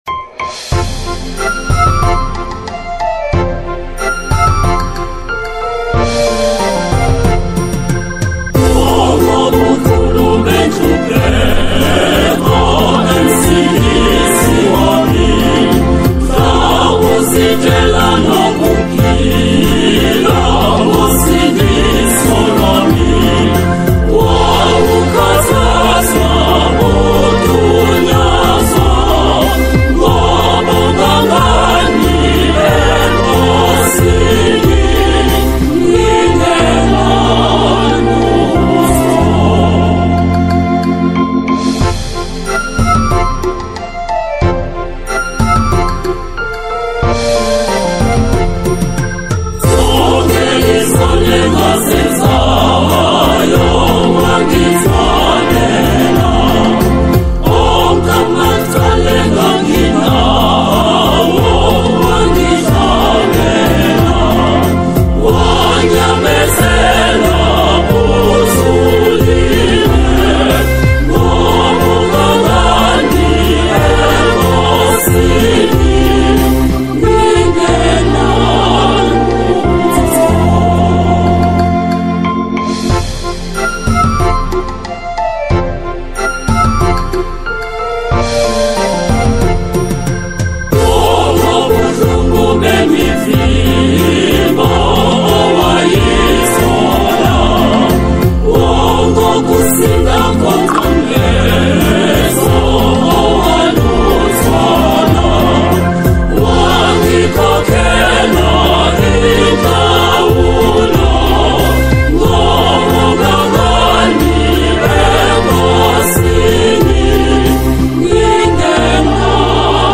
a powerful and moving song
traditional Catholic hymn